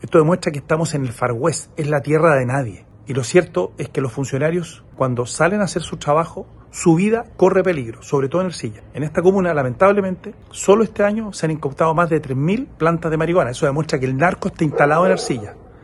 El diputado de Amarillos Andrés Jouannet afirmó que Ercilla parece “tierra de nadie” y emplazó al ministro de Seguridad Pública, Luis Cordero, a visitar la zona.